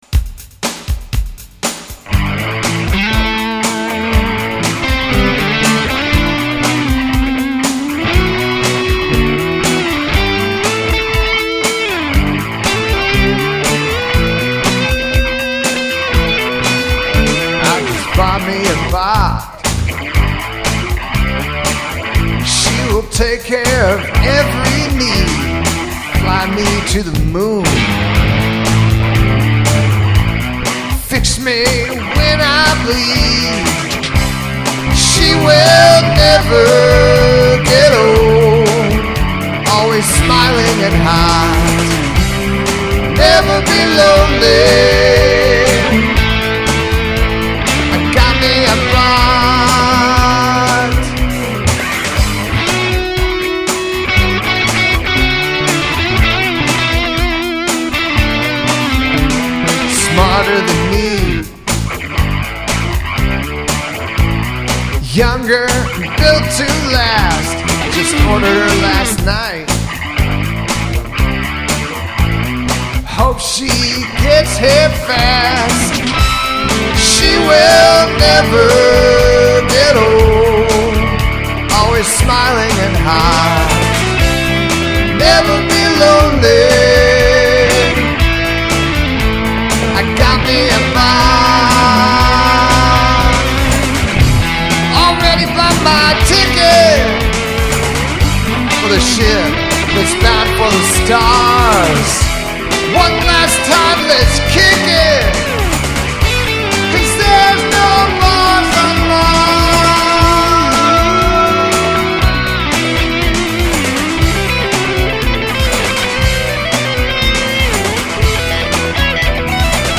120 b/m 7/26/18